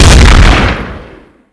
weapons